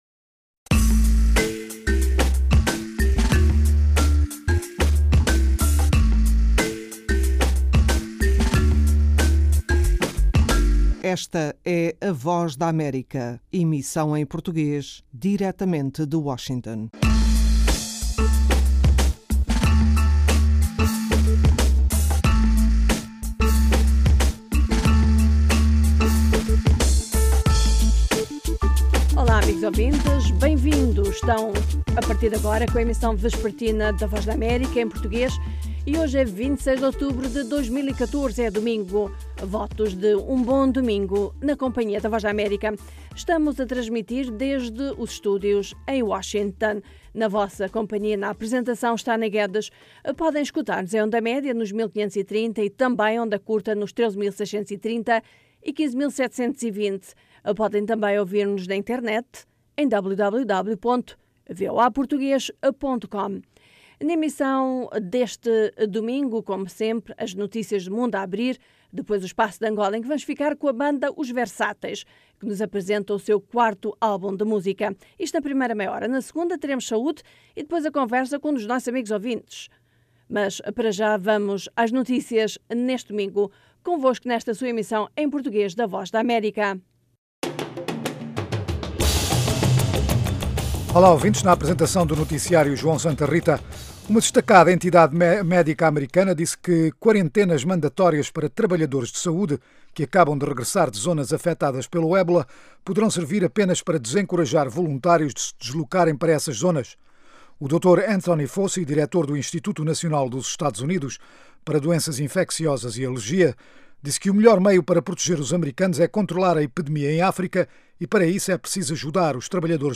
Sábados - mesa redonda sobre tema dominante da política angolana, música americana e notícias do dia.